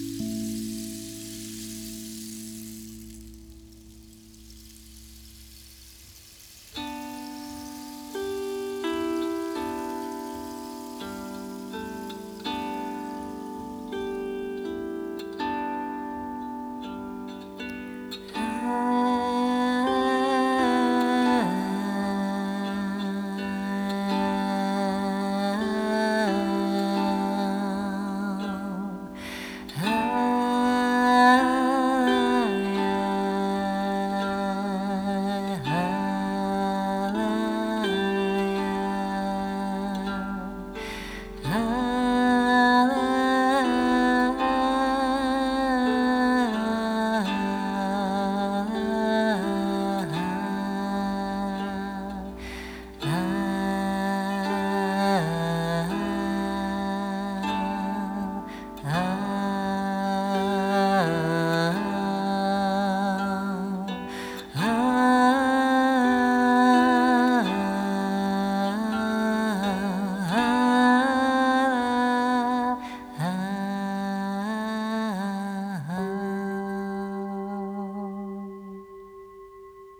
Není nutné soustředit se na význam slov – stačí nechat na sebe působit jejich melodii a hudební tok.
Audio ukázka relaxace